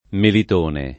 [ melit 1 ne ]